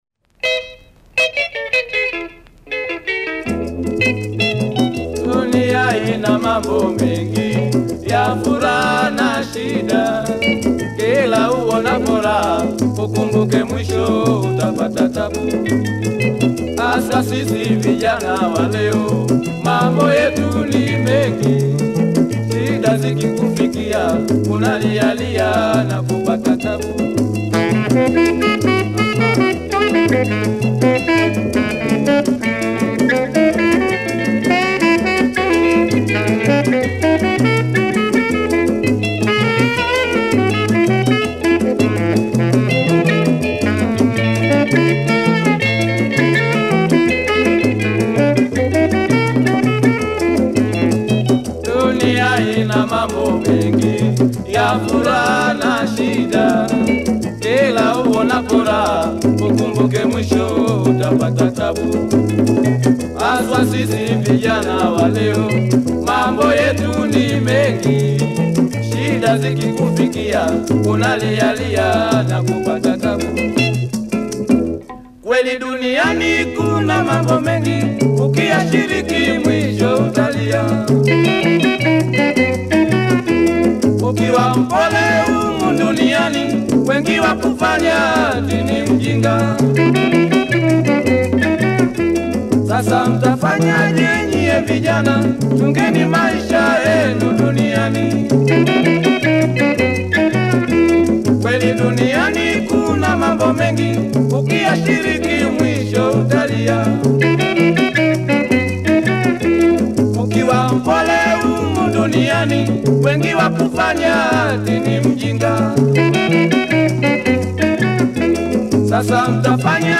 soothing vocals